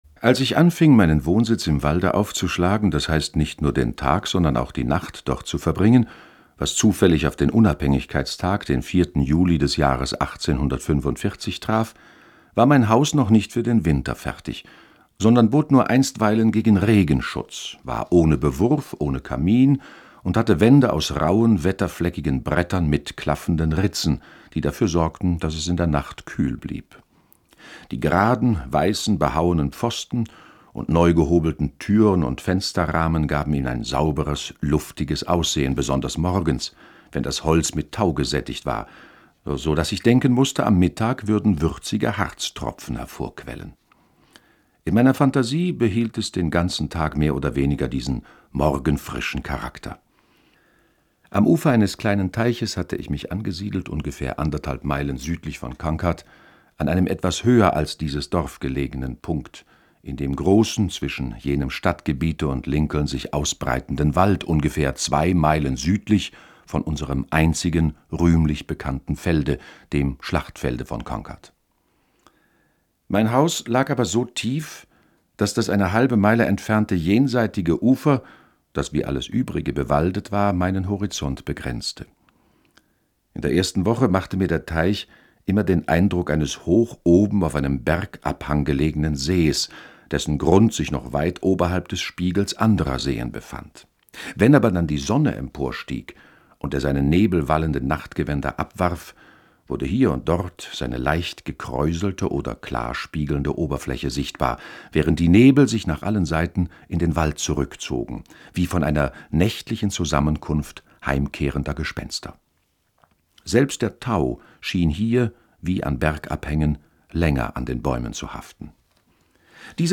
Henry David Thoreau: Walden oder Leben in den Wäldern (3/11) ~ Lesungen Podcast